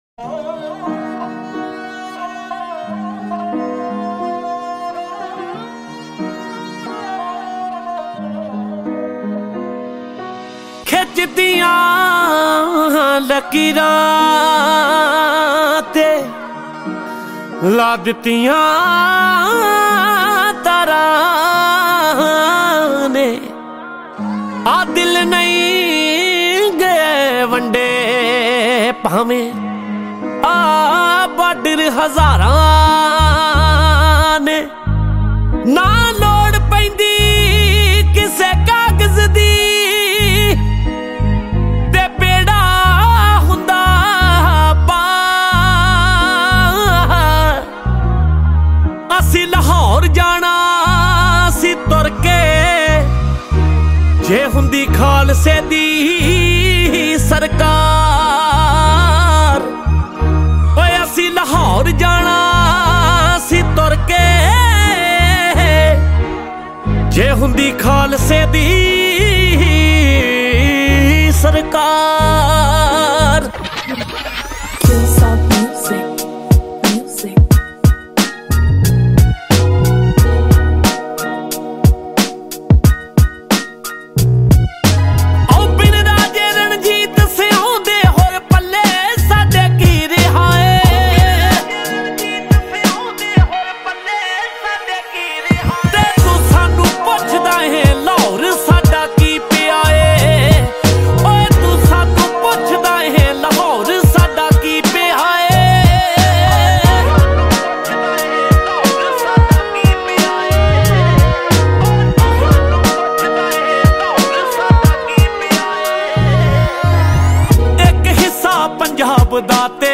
Punjabi Single Track song